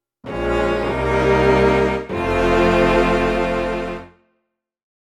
Identité sonore